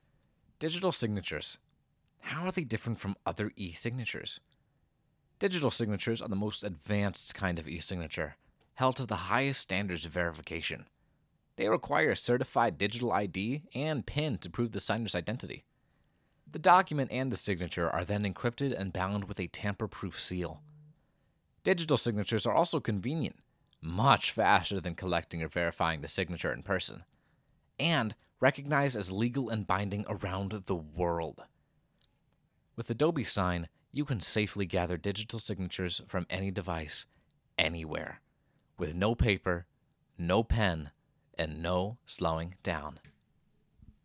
Wide range from adolescent to adult male.
Commercial
I have a naturally deep voice for authentic natural reads.